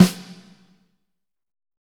Index of /90_sSampleCDs/Northstar - Drumscapes Roland/SNR_Snares 2/SNR_P_C Snares x